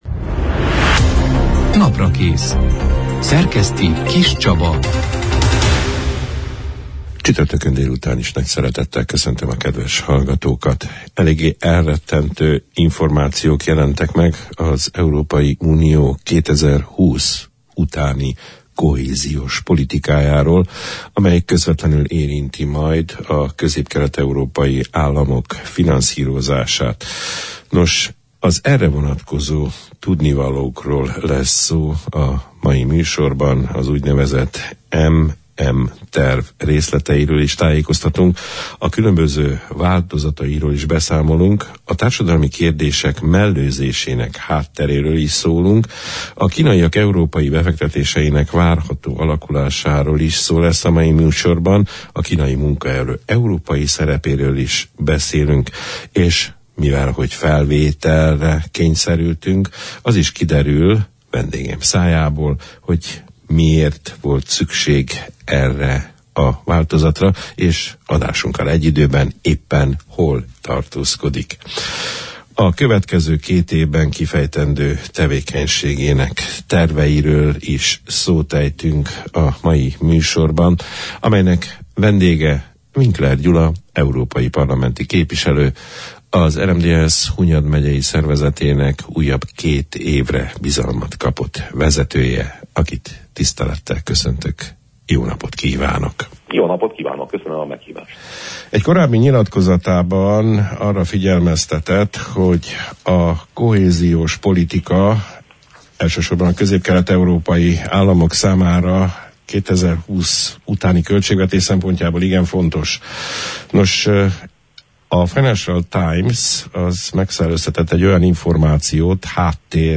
A kohéziós politika 2020 utánra vonatkozó adatainak valódiságáról, a közép – kelet európai államokra vonatkozó, vészjósló előrejelzéseiről, az úgynevezett M&M Terv részleteiről, különböző változatairól, a társadalmi kérdések mellőzésének hátteréről, a kínaiak európai befektetéseinek alakulásairól, a kínai munkaerő európai szerepének kiterjesztési esélyeiről, londoni tárgyalásainak témáiról beszélgettünk az április 26 – án, csütörtökön elhangzott Naprakész műsorban Winkler Gyula EP képviselővel, az RMDSZ Hunyad megyei szervezetének elnökével.